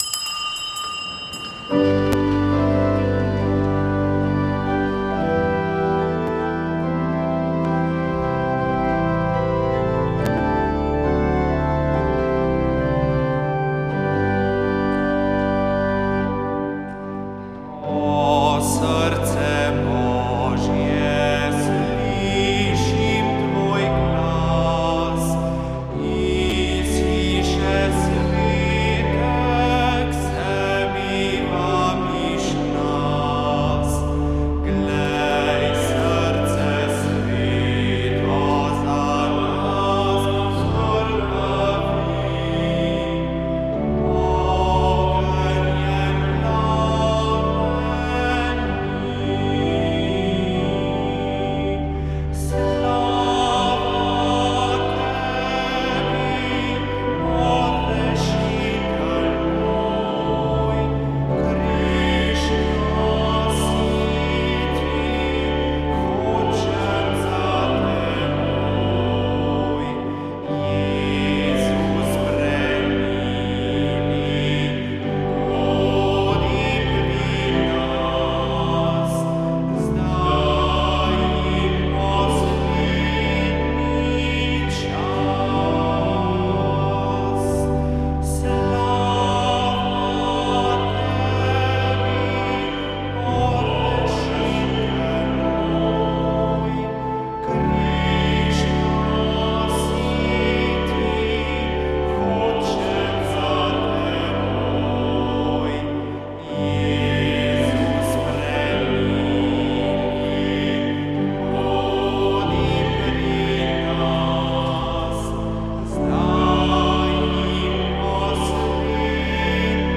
S petjem sta sodelovala: APZ sv. Cecilije, Mladinski mešani zbor in orkester
Sveta maša